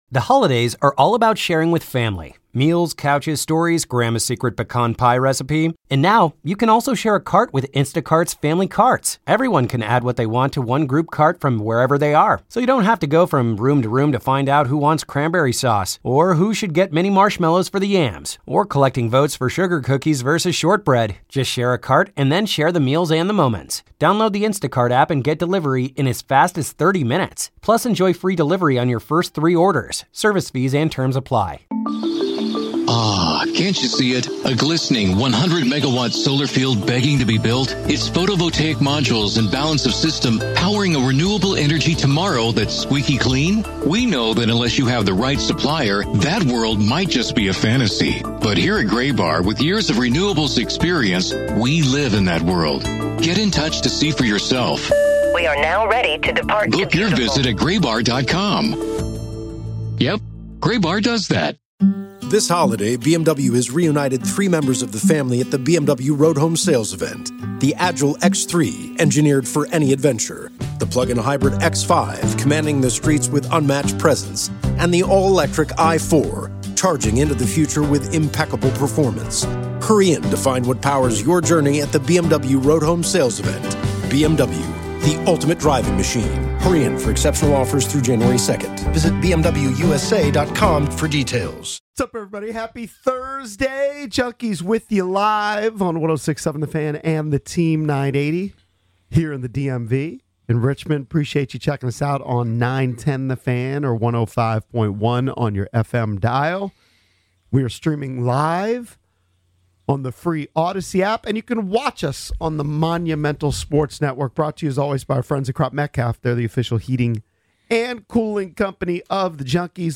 For more than 25 years, The Junks have owned Washington D.C. sports radio, covering Commanders, Nationals, Capitals, Wizards, Hokies, Terrapins, and Hoyas news.